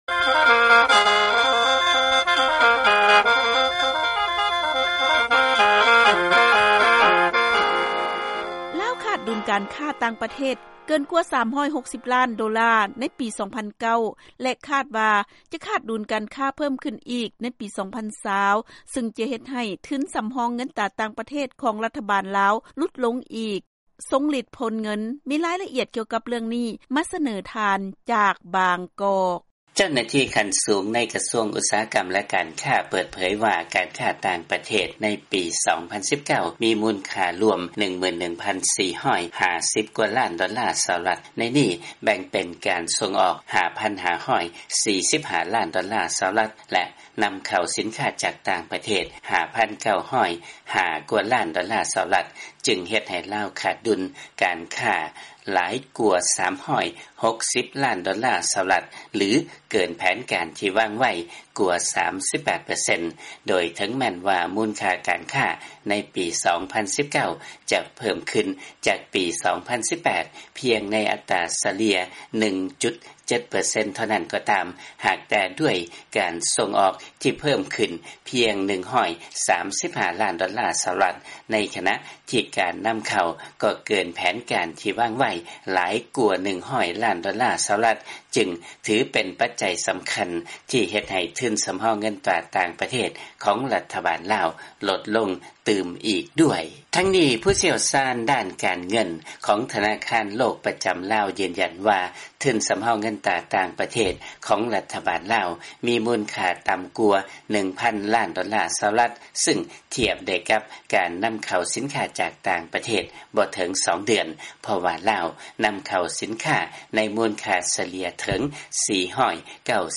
ເຊີນຟັງລາຍງານ ລາວ ປະເຊີນ ກັບການຂາດດຸນການຄ້າ ເກີນກວ່າ 360 ລ້ານໂດລາ ໃນປີ 2019 ແລະຈະເພີ້ມຂຶ້ນ ໃນປີ 2020